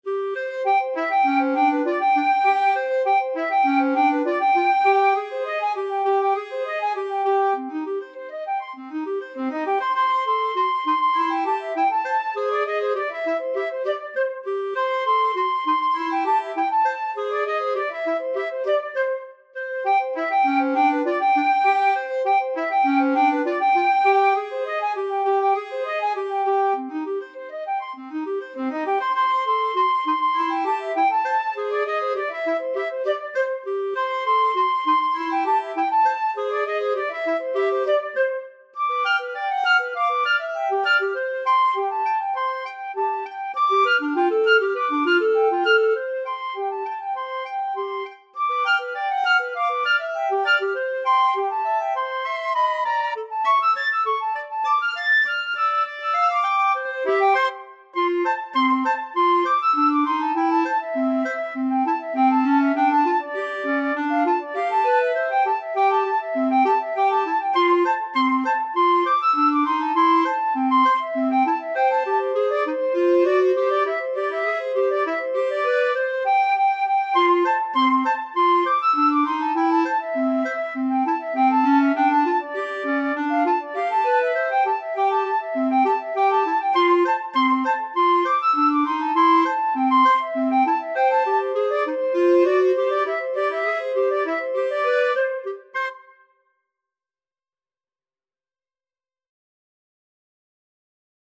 4/4 (View more 4/4 Music)
Energico =200
Jazz (View more Jazz Flute-Clarinet Duet Music)